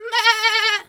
pgs/Assets/Audio/Animal_Impersonations/sheep_2_baa_high_03.wav at 7452e70b8c5ad2f7daae623e1a952eb18c9caab4
sheep_2_baa_high_03.wav